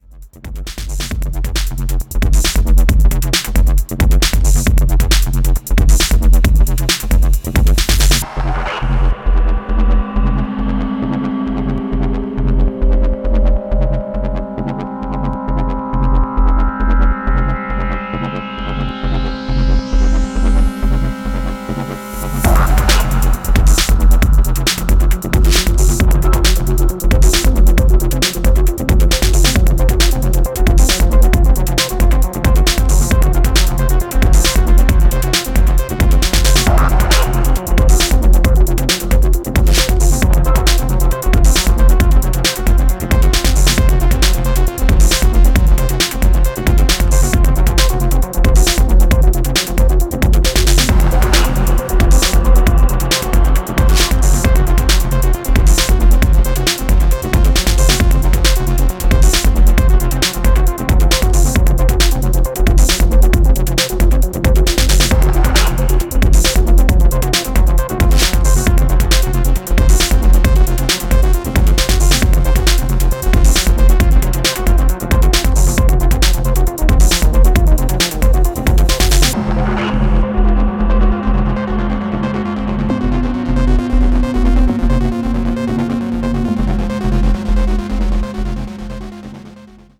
Join us on this second Electro-Acid adventure!